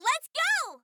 Voice Sample
Letsgodaisy.oga.mp3